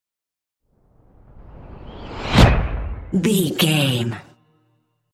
Dramatic whoosh to hit airy trailer
Sound Effects
Fast paced
In-crescendo
Atonal
dark
intense
tension
woosh to hit